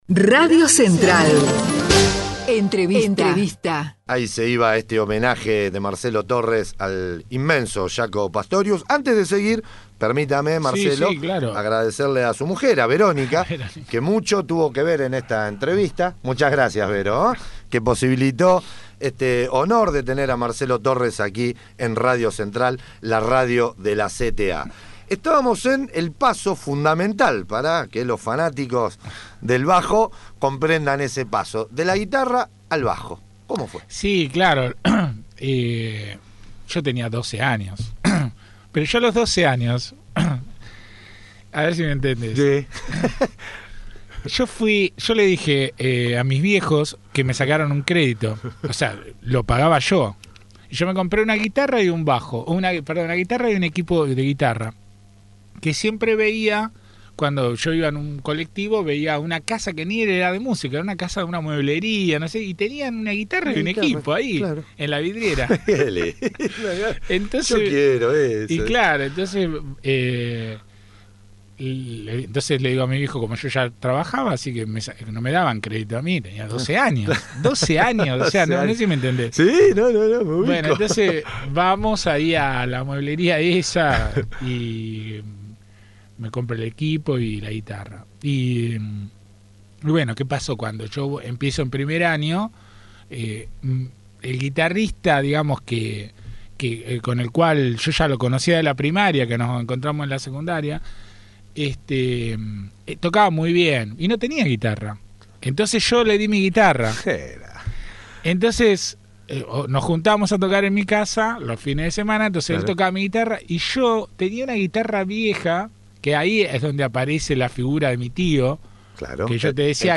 El talentoso bajista también repasa su trayectoria junto a Luis Alberto Spinetta, Carlos "Indio" Solari, Lito Vitale, Adriana Varela, Castiñeira de Dios, entre otros grandes artistas.